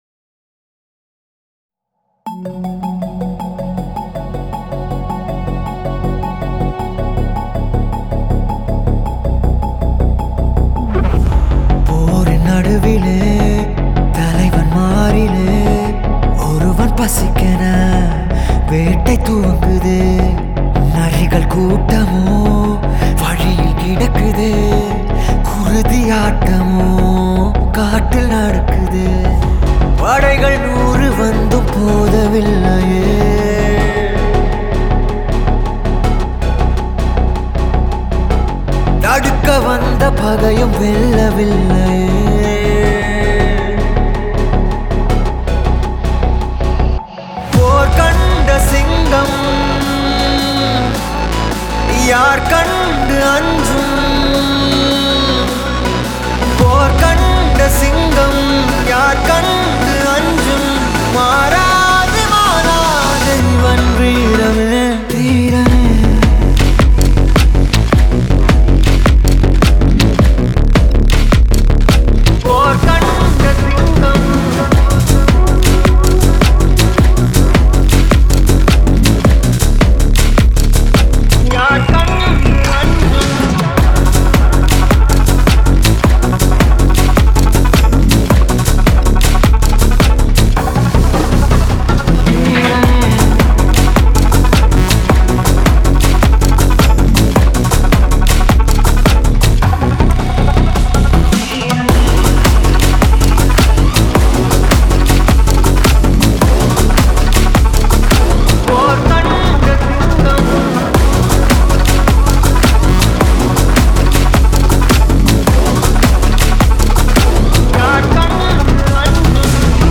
EDM Version